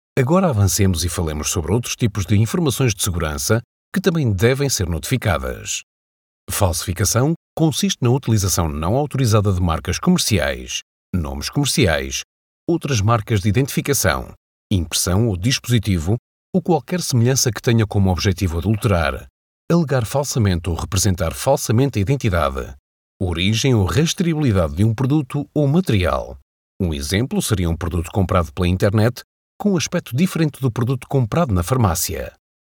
Naturelle, Enjouée, Amicale, Chaude, Corporative
E-learning